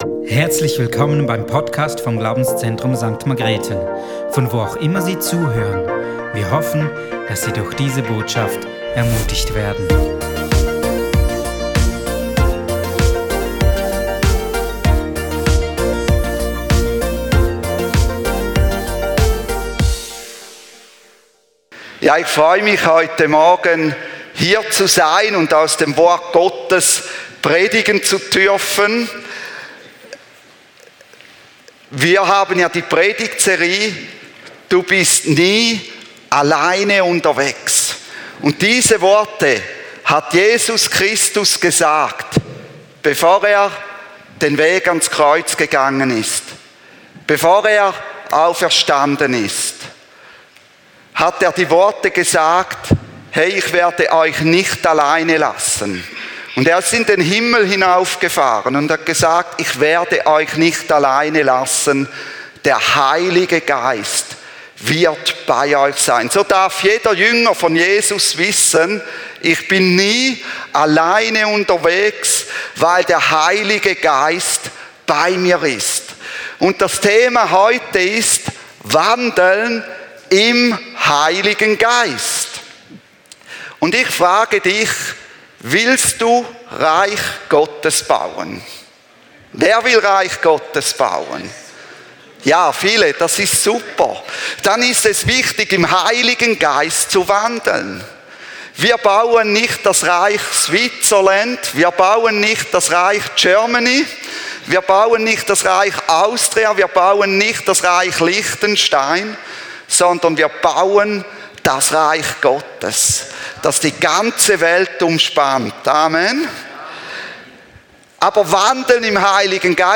Glaubenszentrum St.Margrethen | Predigten